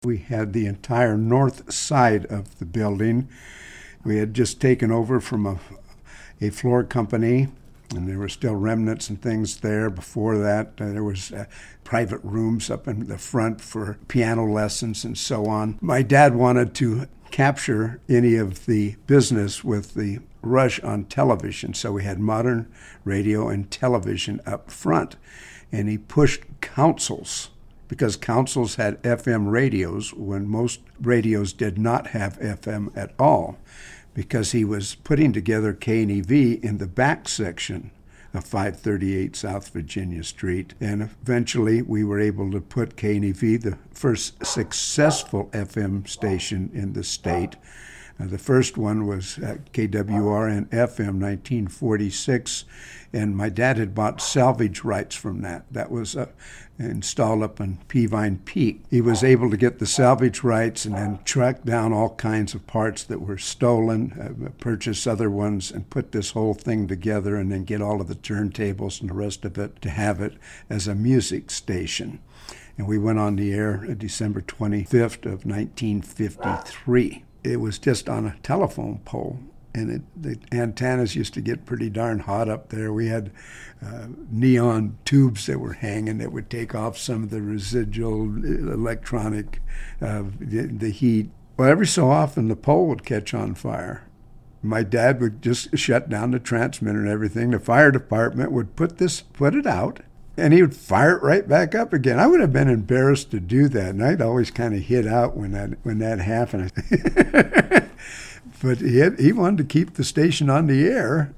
In a 2015 interview